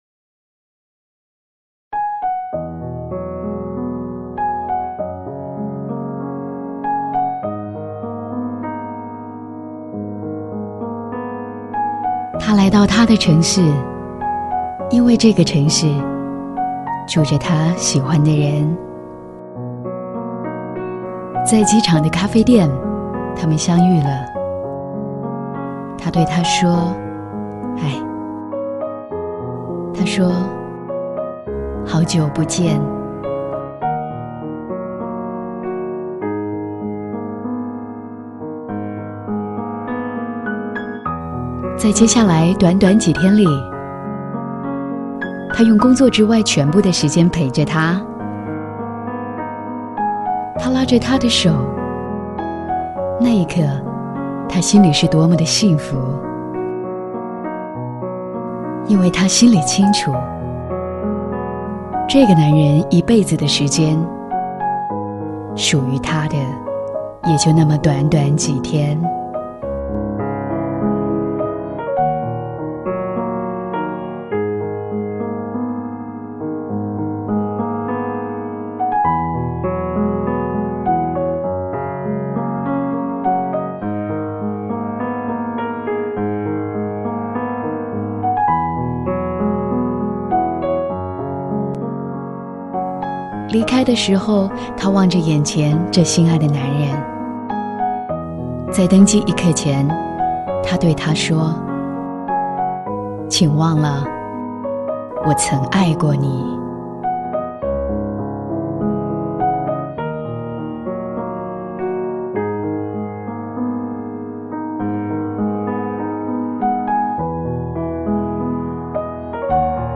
钢琴独白版